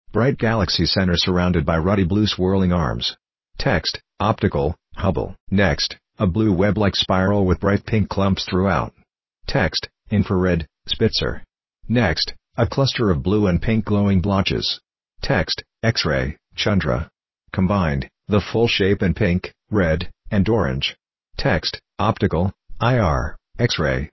• Audio Description